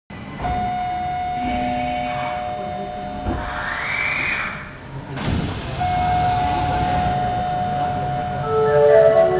・9000形ドアチャイム
【西側】開扉時（9秒：53.2KB）
開扉時は２回、閉扉時は１回流れます。西側は２打点、東側は３打点となっています。